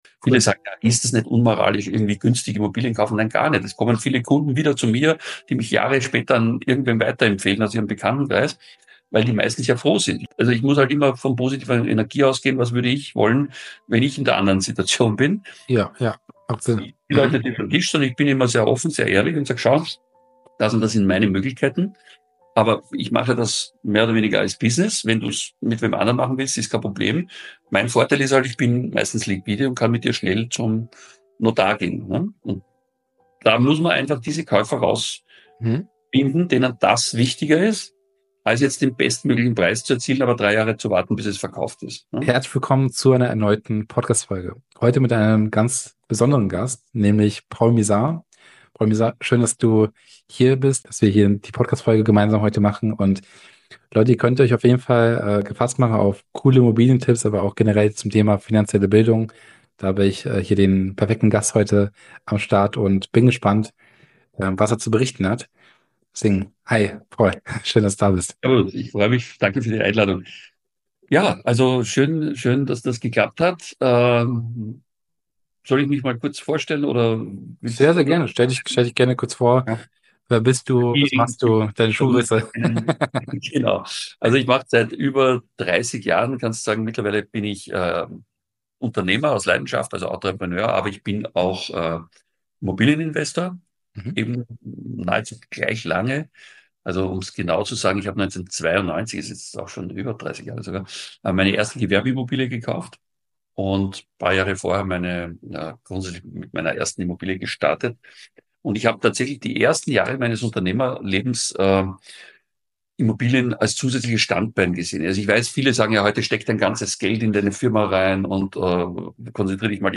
#54 So kriegst du jede Immobilie! Interview